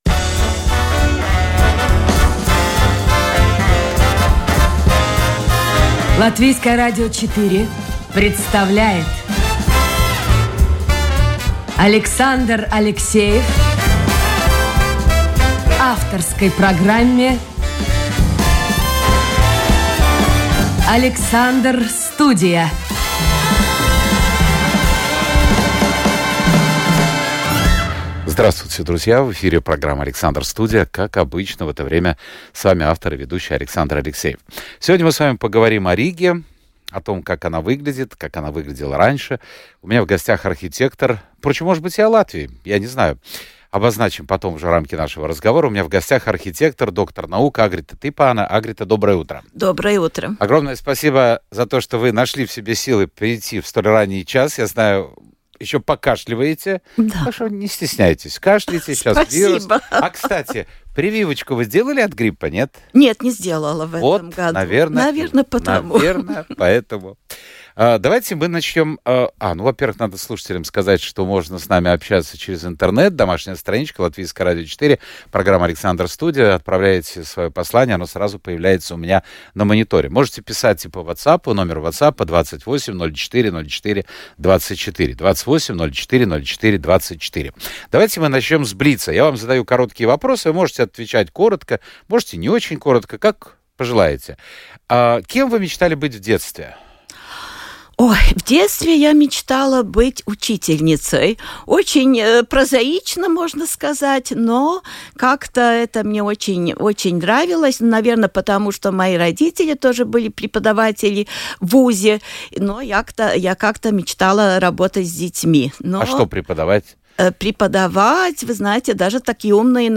Живой и непринужденный диалог со слушателями, неформальный разговор с известными людьми, ТОК-ШОУ с участием приглашенных экспертов о самых невероятных явлениях нашей жизни.